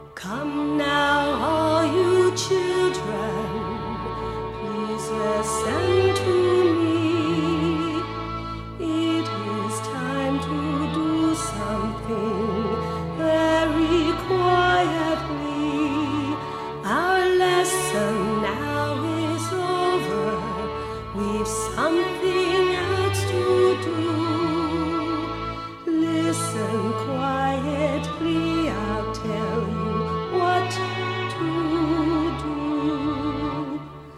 Genre: Alternative & Punk.